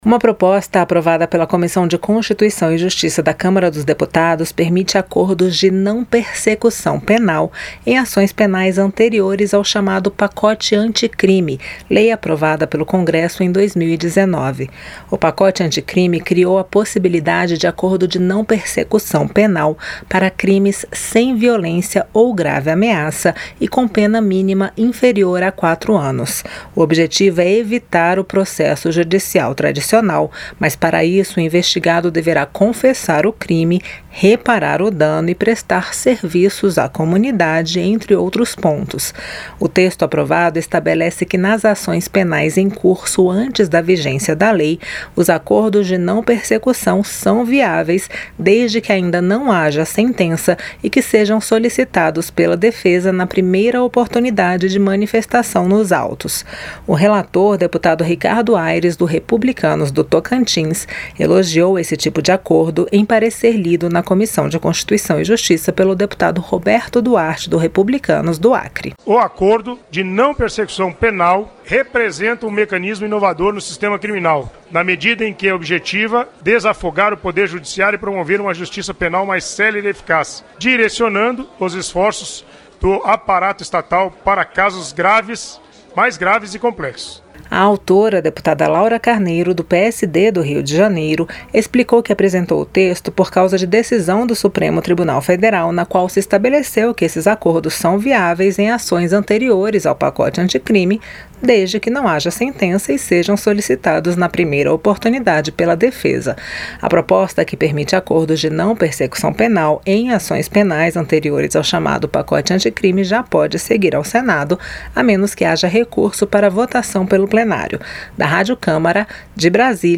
COMISSÃO APROVA PERMISSÃO PARA ACORDO DE NÃO PERSECUÇÃO PENAL EM AÇÕES ANTERIORES AO PACOTE ANTICRIME. ENTENDA NA REPORTAGEM